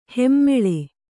♪ hemmeḷe